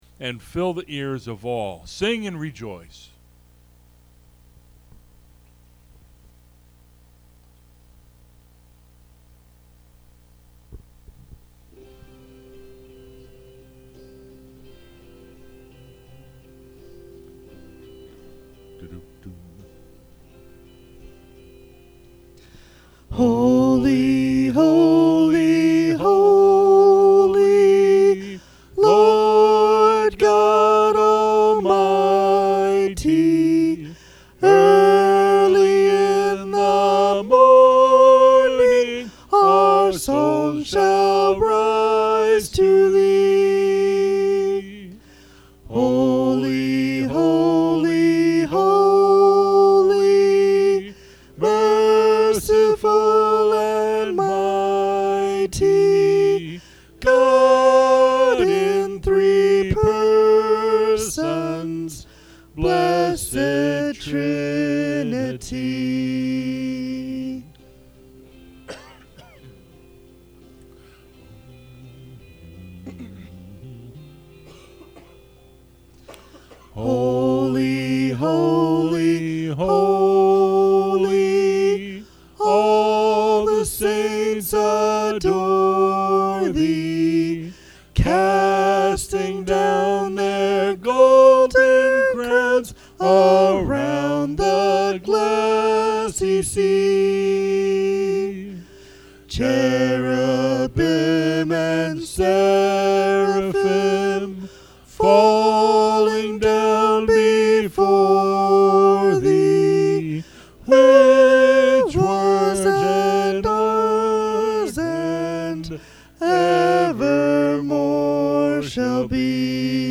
Part 2 Revelation 21:9-22:5 | Crossbridge Community Church